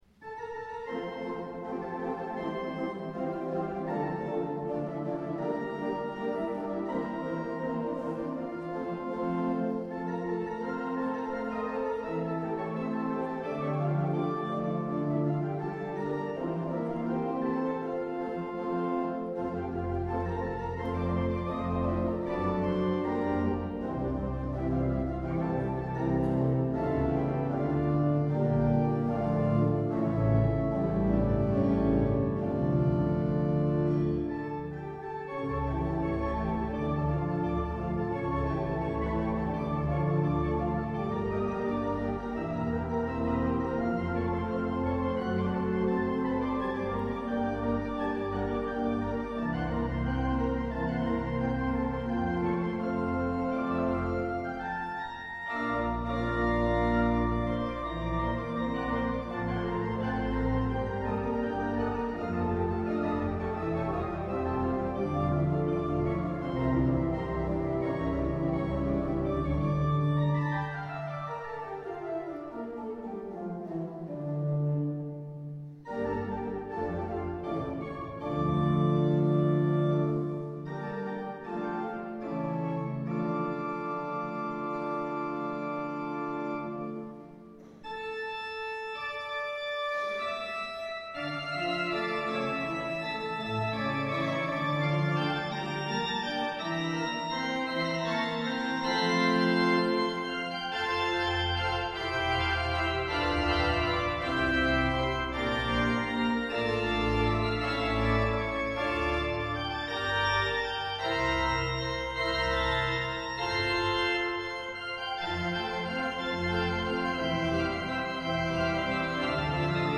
Concierto celebrado en Collbató del 50º aniversario del Órgano del Sol Mayor de Marbella.